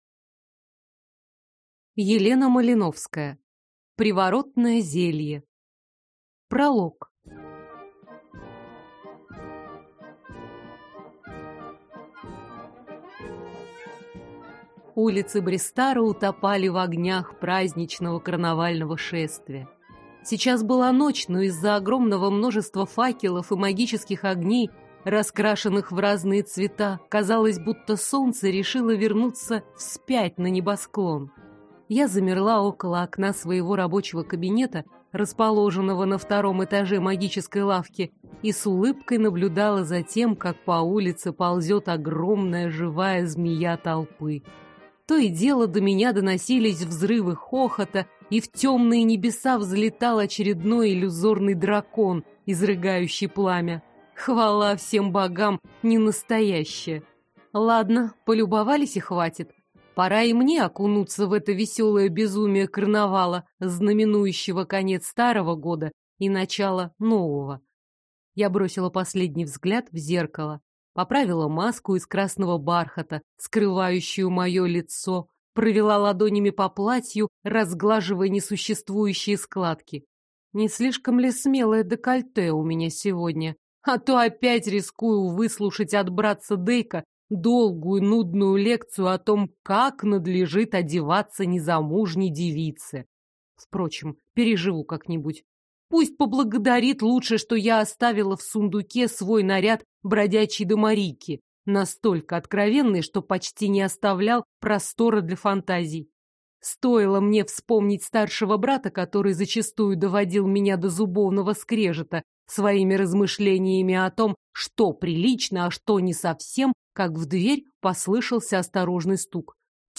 Аудиокнига Приворотное зелье | Библиотека аудиокниг